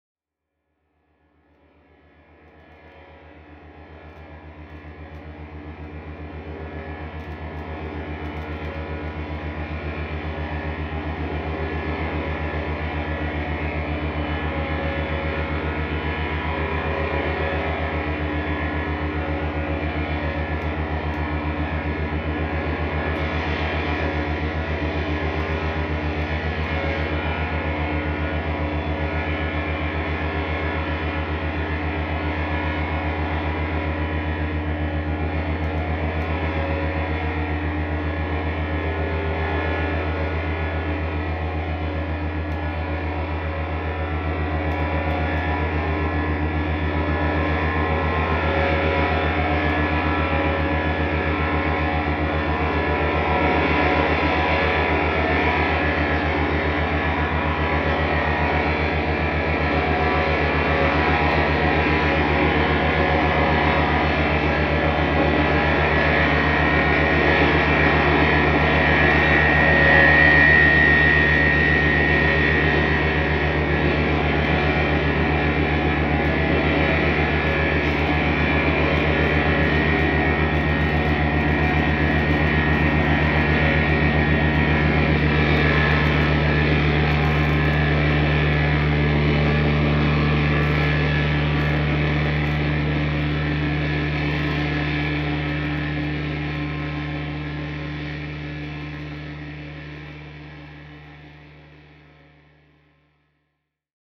Feedback produced by the action of the surface transducer. Audio is from the pickups, not from contact microphones.